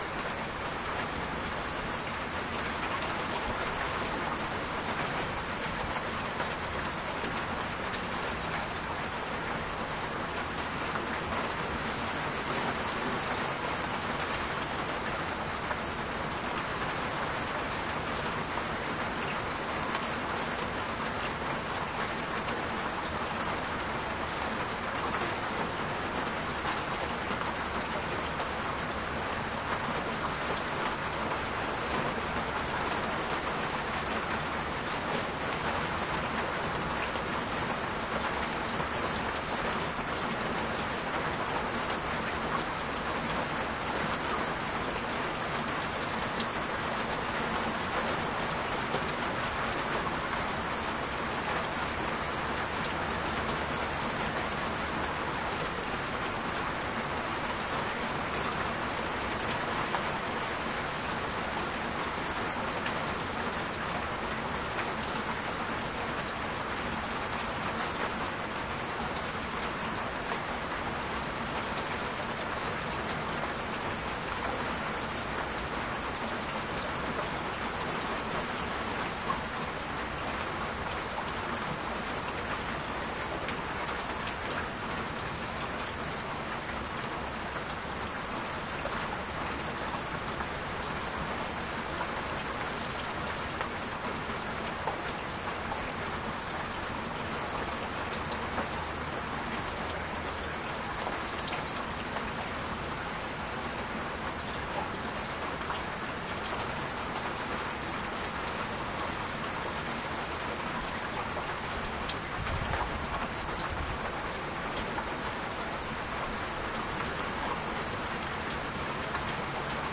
浴缸里的水在流动，很远 - 声音 - 淘声网 - 免费音效素材资源|视频游戏配乐下载